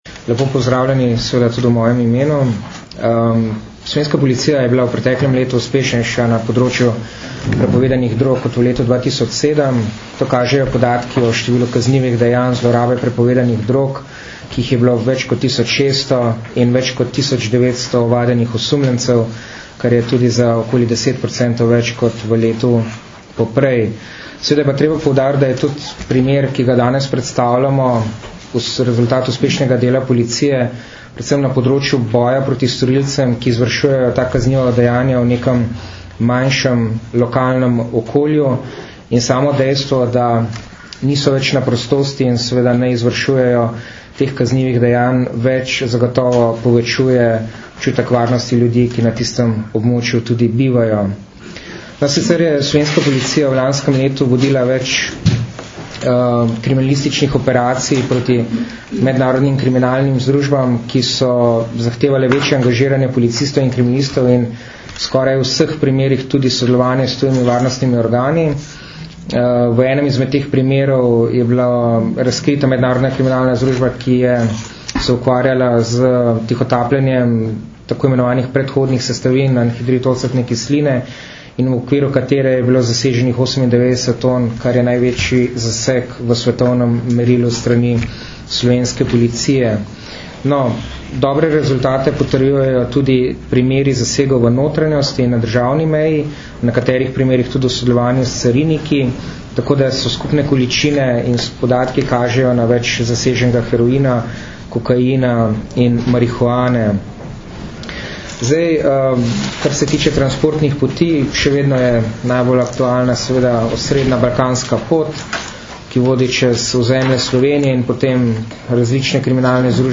Na današnji novinarski konferenci na Policijski upravi Nova Gorica sta predstavnika Generalne policijske uprave in PU Nova Gorica podrobneje opisala rezultate nedavno uspešno zaključene preiskave kriminalne združbe, ki se je na območju Nove Gorice in okolice ukvarjala s preprodajo prepovedanih drog.
Izjava